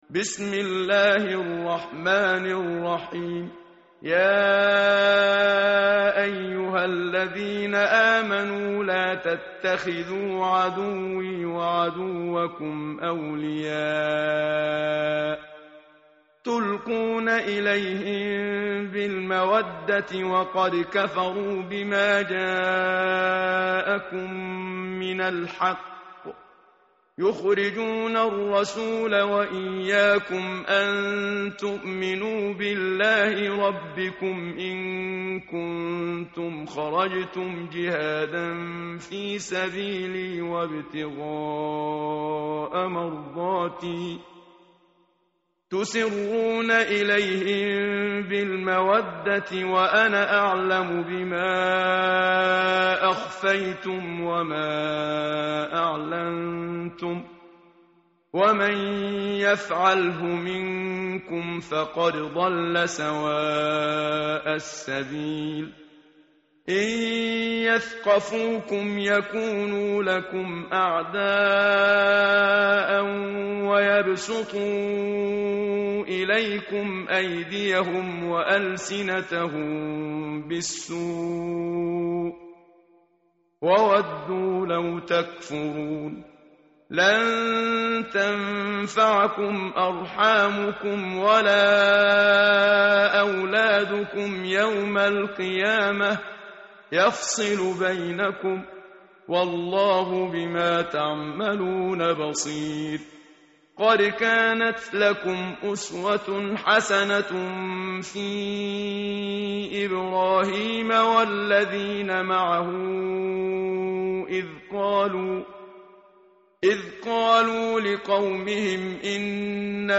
tartil_menshavi_page_549.mp3